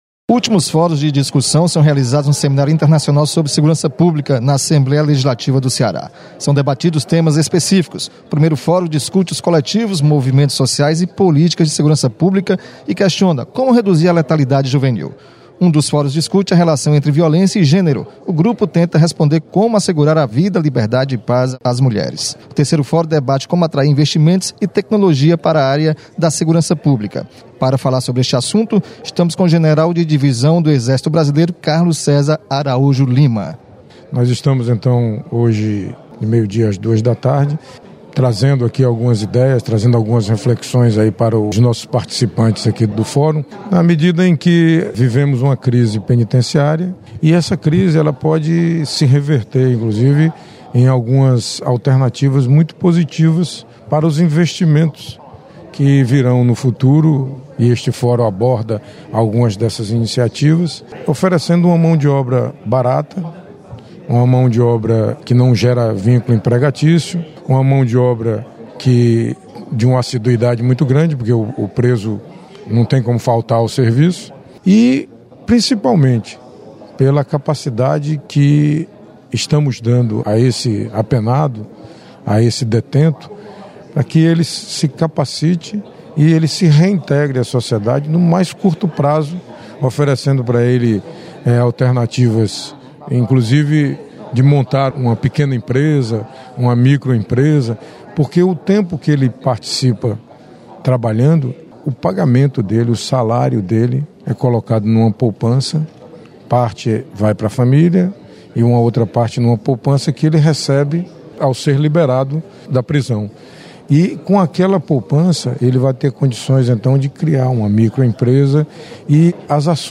Debate sobre redução da letalidade juvenil e violência contra a mulher são temas dos fóruns desta sexta-feira no Seminário Internacional sobre Segurança Pública. Repórter